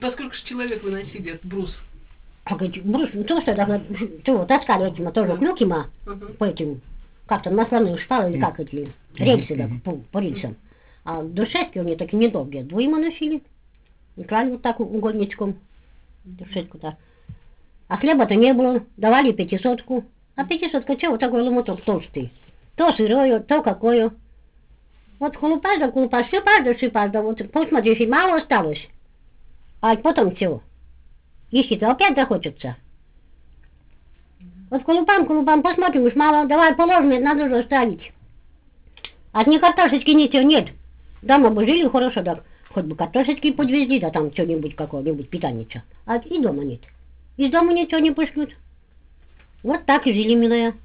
Vjatskie govory
Band 8 der Supplements zum BFF setzt die mit Band 1 begonnene Veroeffentlichung authentischer Dialekttexte aus dem Norden Russlands fort.
Die 24 Texte spiegeln die dialektale Vielfalt der Region wider.